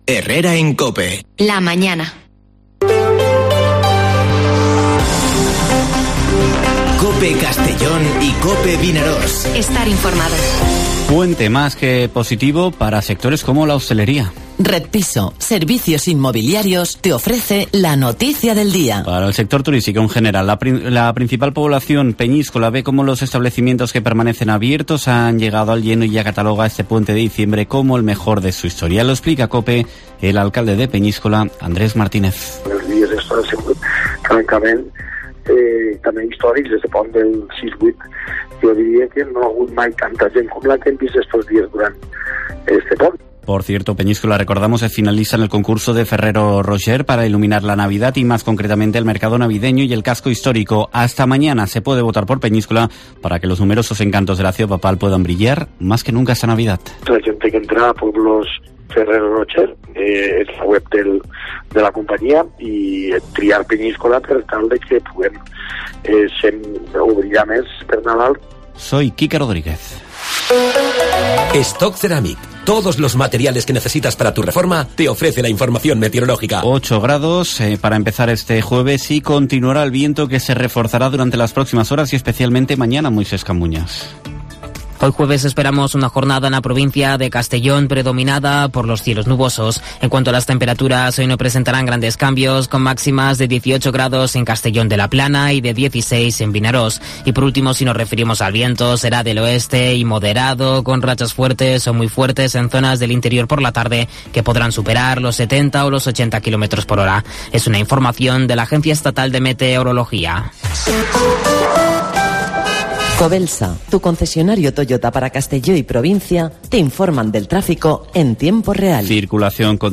Informativo Herrera en COPE en la provincia de Castellón (09/12/2021)